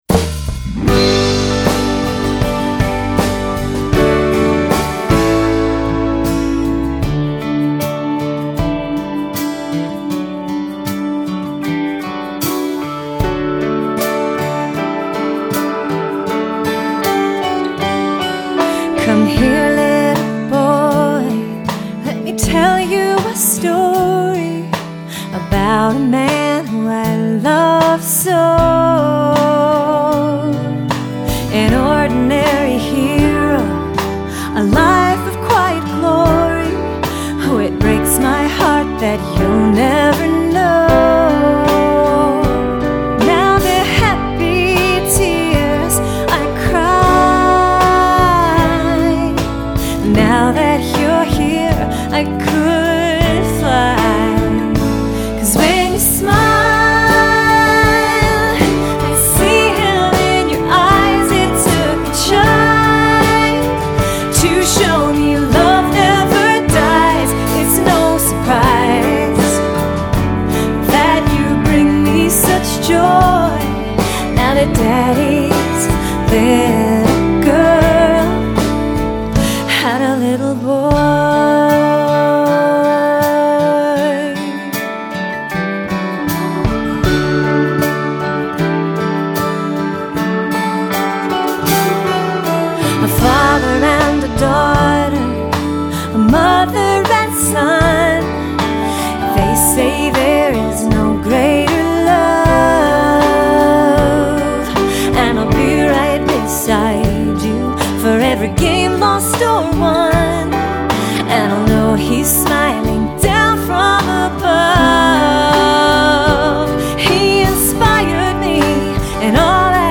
although it has quite a breezy feel
sings the background vocals